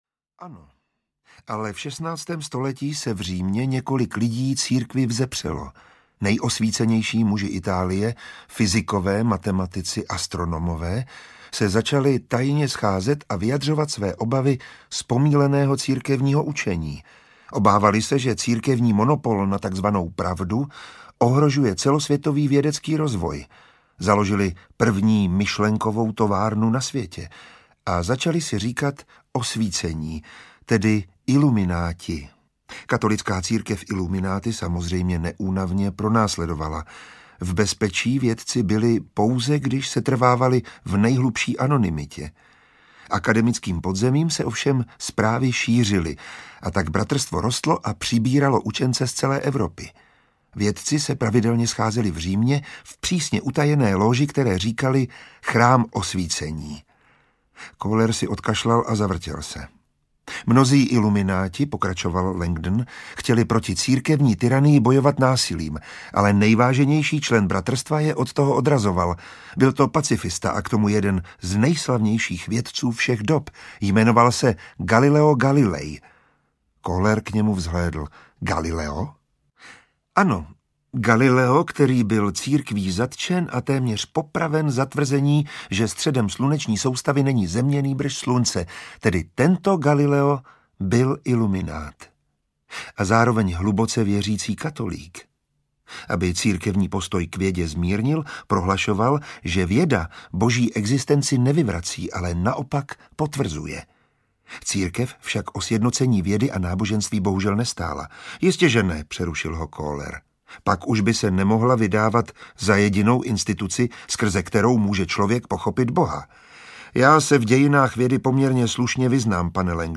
Andělé & Démoni audiokniha
Ukázka z knihy
• InterpretMiroslav Táborský
andele-demoni-audiokniha